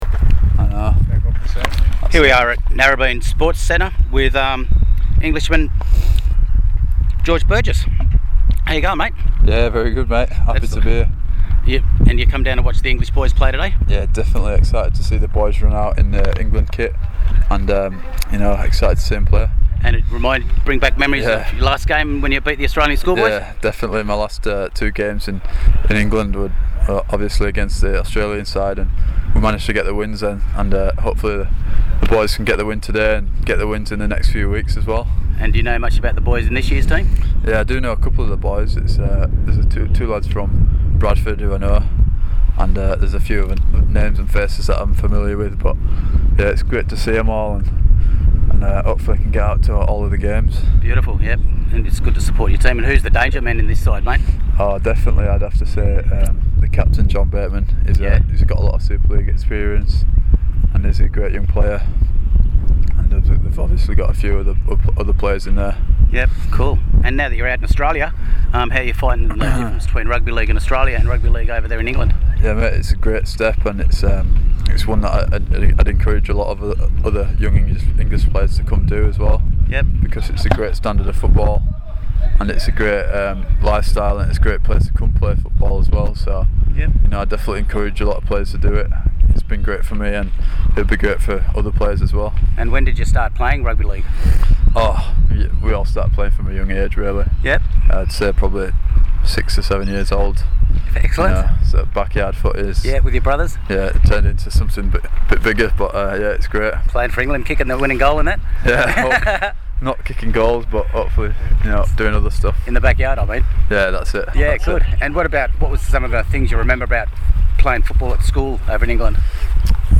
George Burgess InterviewGeorge Burgess InterviewINTERVIEW WITH 2010 ENGLAND ACADEMY STAR